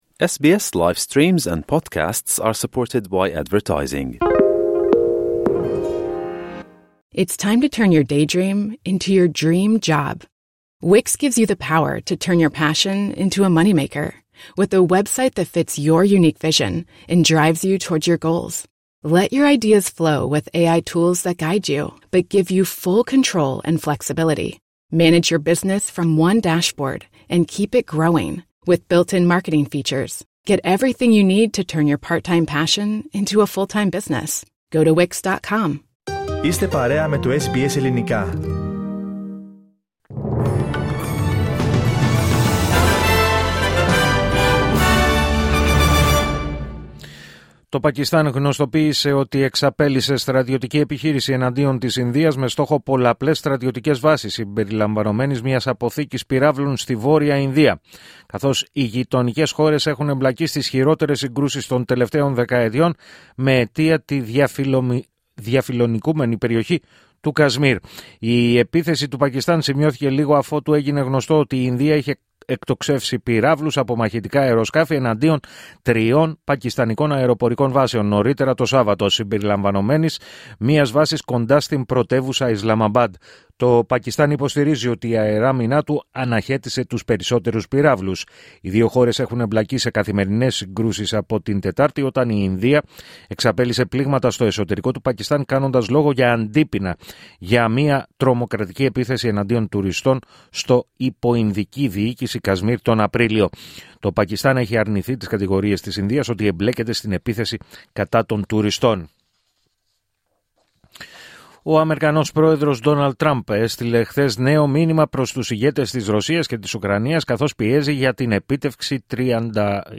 Δελτίο Ειδήσεων Σάββατο 10 Μαΐου 2025